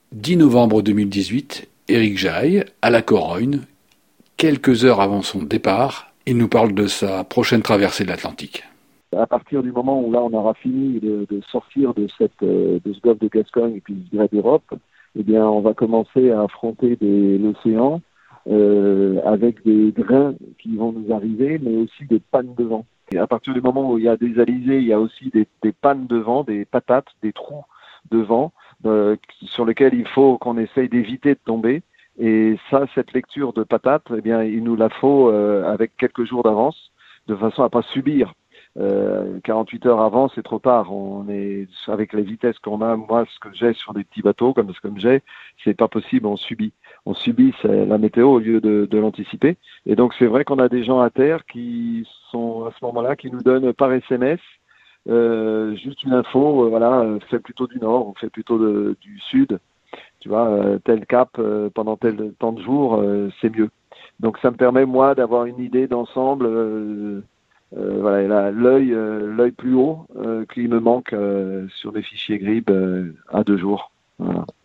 Bibliothèque d’interviews et de PODCASTS :